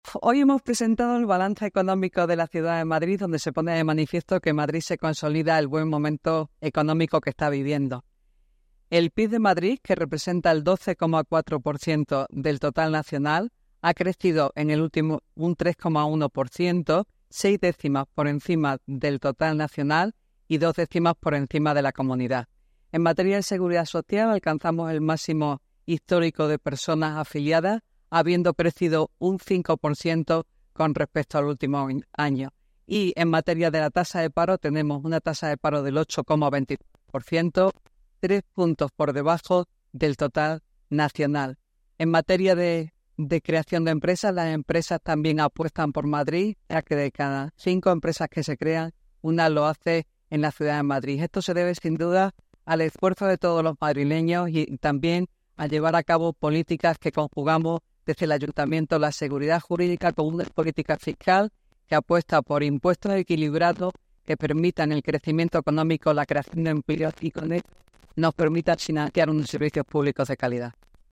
Nueva ventana:La delegada de Economía, Innovación y Hacienda, Engracia Hidalgo